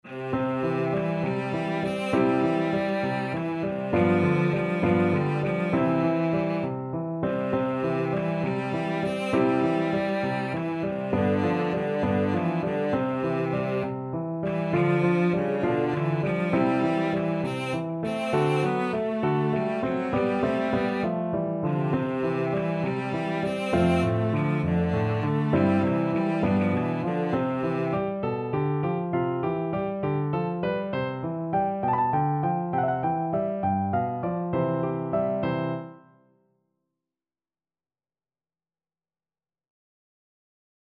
Cello
C major (Sounding Pitch) (View more C major Music for Cello )
~ = 100 Fršhlich
6/8 (View more 6/8 Music)
Classical (View more Classical Cello Music)